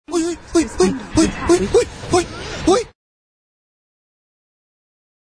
голосовые
Чат колеса